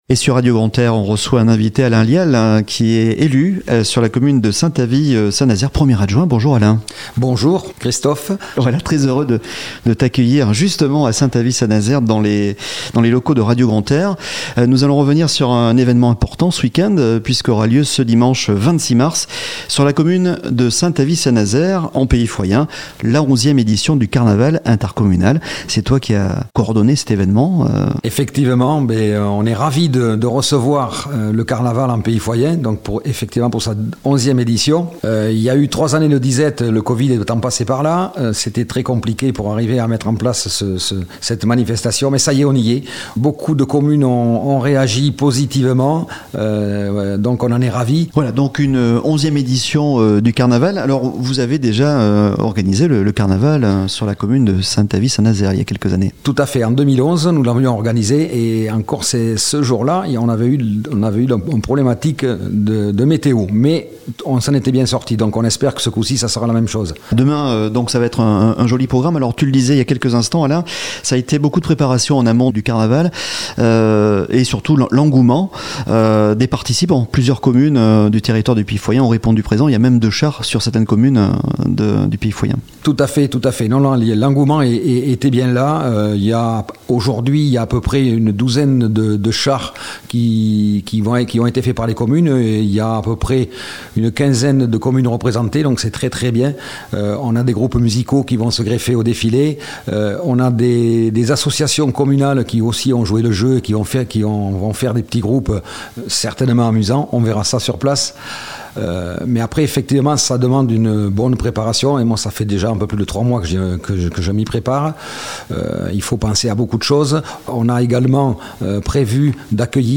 Interview de Alain LIAL 11ème Carnaval en Pays Foyen le 26 Mars 2023
On revient sur l'évènement "Carnaval en Pays Foyen 2023" avec Alain LIAL 1er adjoint à la commune de Saint Avit Saint Nazaire sur Radio Grand "R" ????